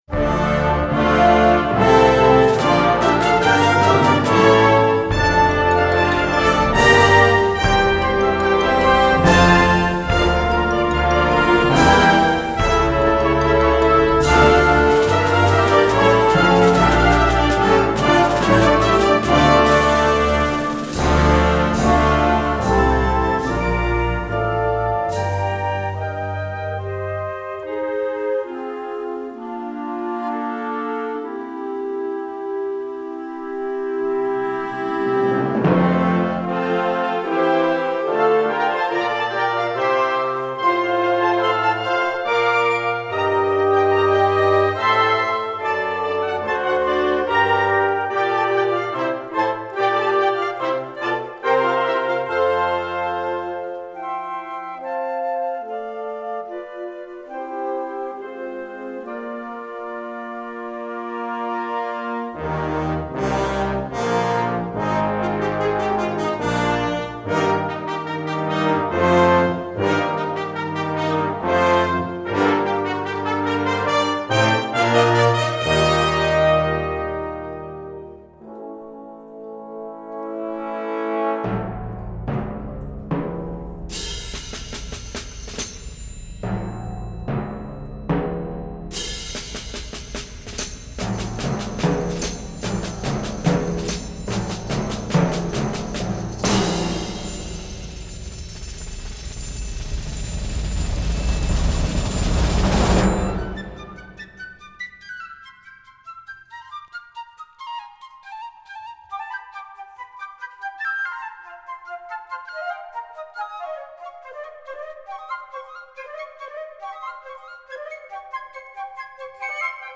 for String Quartet and Pre-Recorded Audio